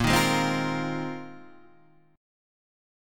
A# 7th Suspended 2nd Suspended 4th